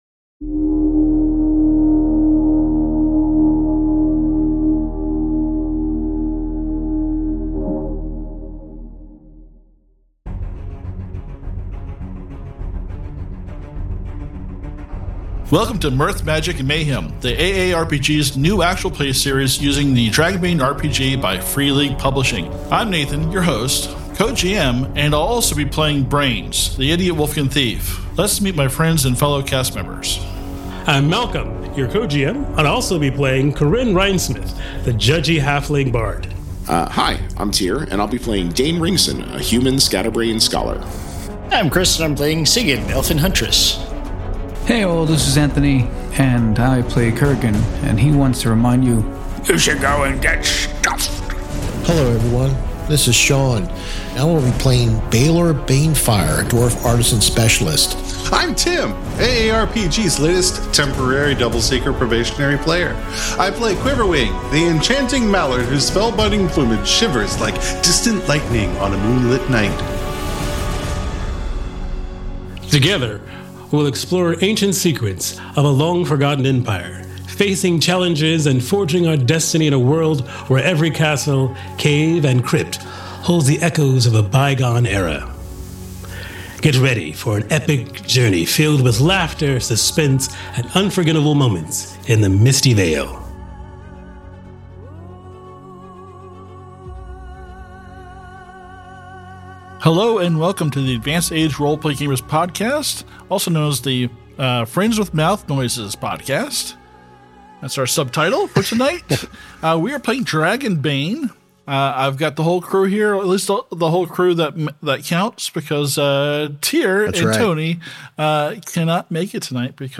Step into the rich tapestry of Dragonbane RPG as our actual play podcast unfolds in the Misty Vale, a region steeped in history as the heart of the Dragon Empire thousands of years ago.